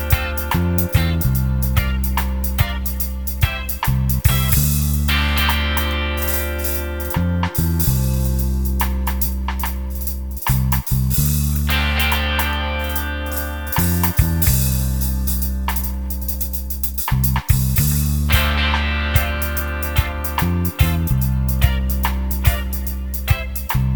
no bass Pop (1980s) 3:36 Buy £1.50